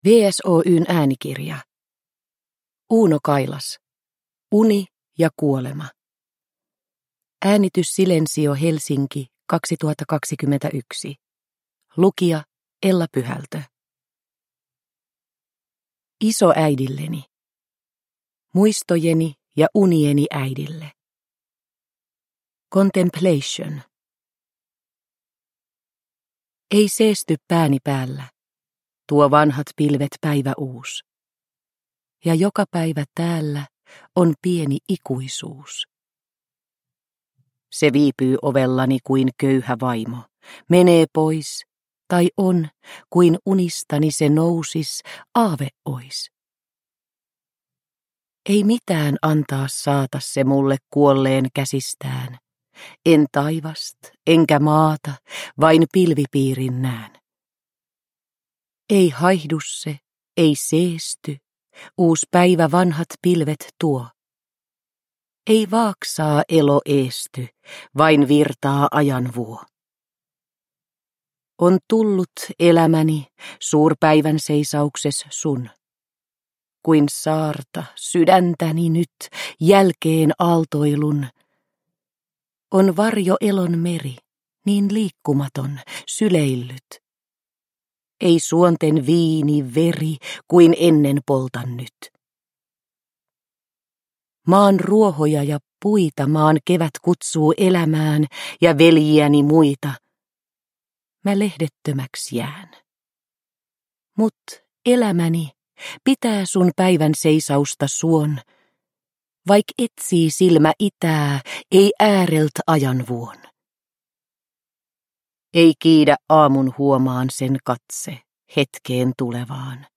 Uni ja kuolema – Ljudbok – Laddas ner